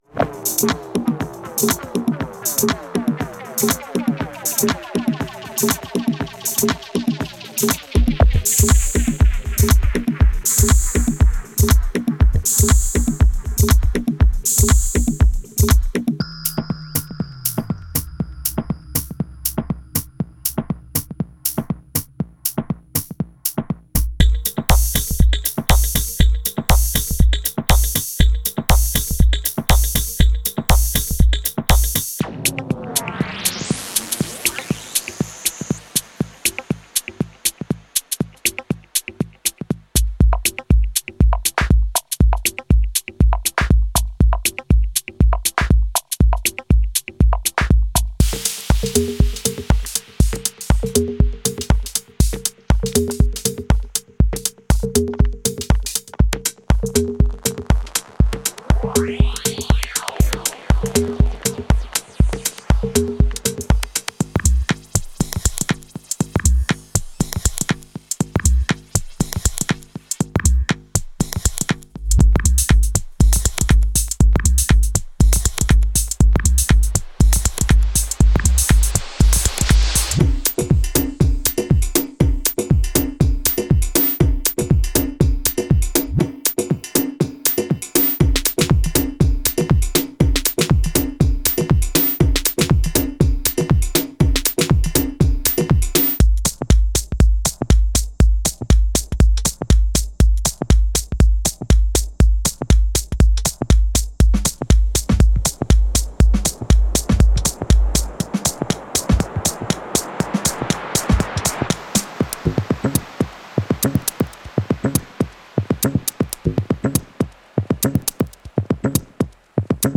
Genre:House
すべて120bpmで収録されており、幅広いアナログおよびモジュラー機材から制作されています。
従来どおり、すべてのサンプルは、ミックスの中でもしっかりと抜けるよう、厳選されたハイエンド機材を通して処理されています。
デモサウンドはコチラ↓
100 Loops:
40 Percussion Loops